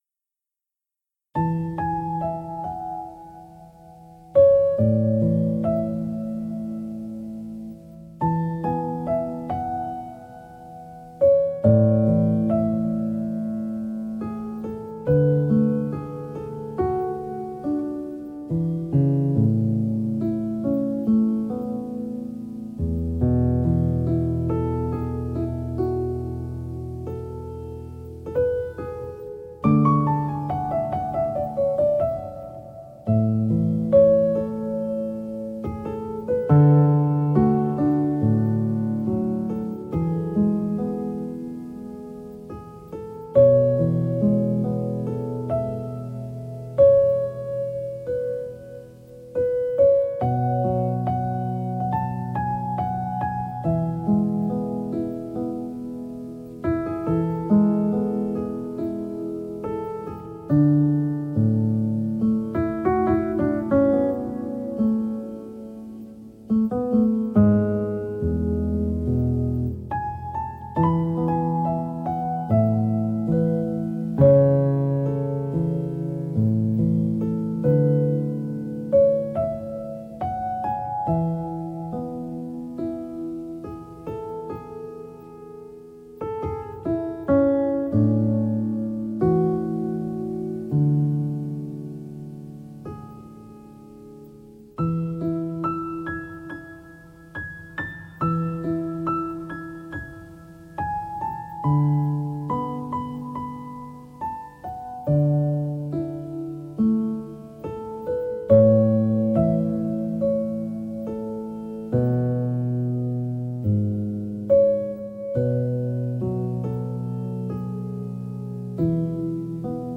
Category: Jazz